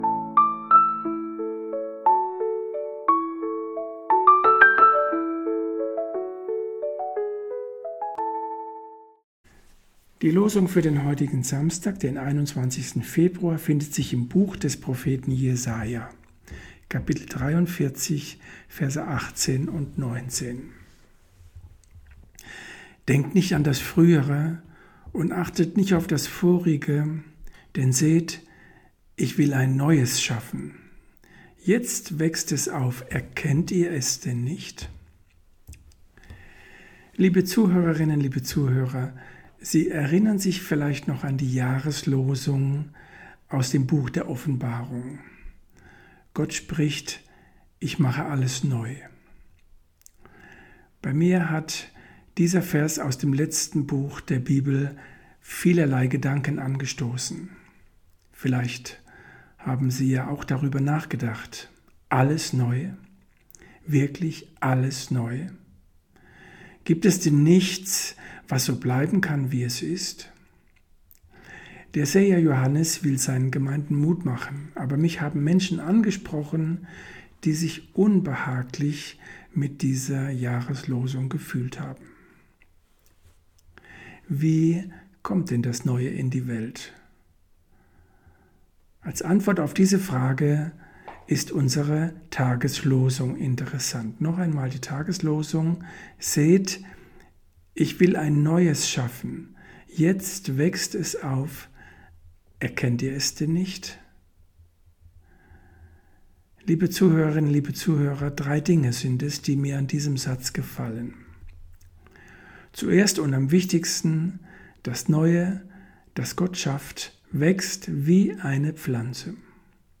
Losungsandacht für Samstag, 21.02.2026 – Prot.